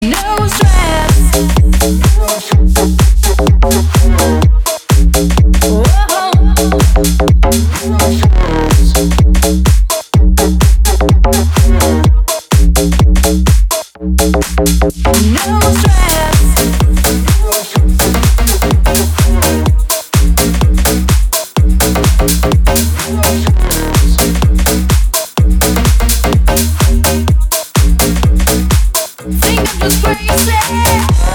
• Качество: 320, Stereo
мужской вокал
deep house
EDM
Club House
энергичные